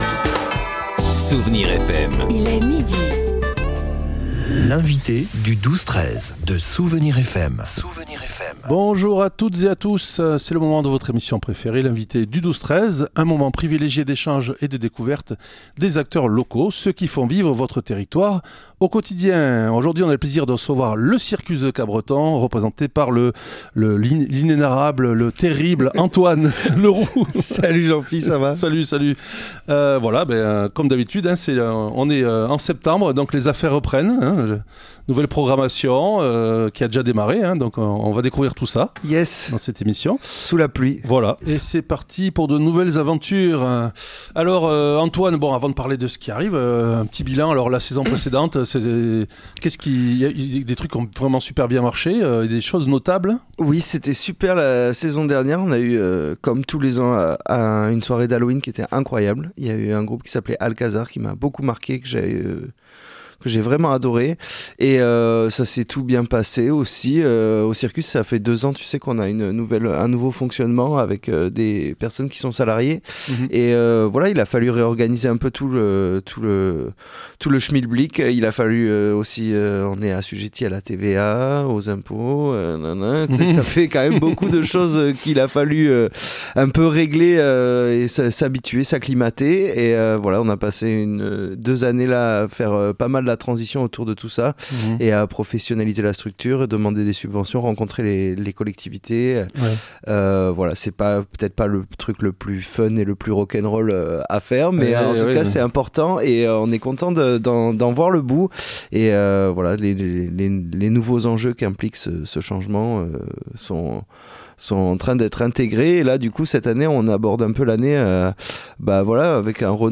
L'invité(e) du 12-13 a recu aujourd'hui Le Circus de Capbreton.
Les incontournables Friends Songs and Beers et la guinguette du dimanche sont de retour… mais cette année, le Circus mise sur l’éclectisme pour nous surprendre ! Pour découvrir les artistes à venir et les nombreuses activités prévues, écoutez-nous en parler avec enthousiasme — on vous dit tout dans la bonne humeur !